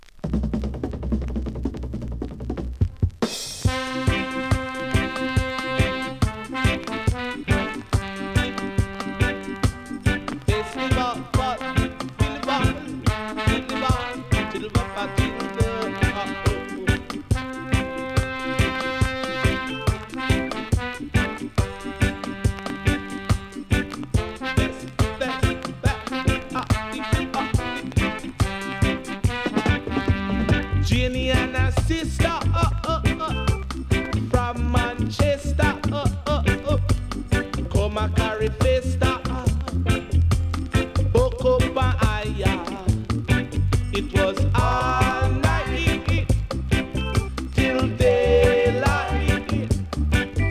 REGGAE 70'S
という、自由でハッピーなラヴ＆ライフソング。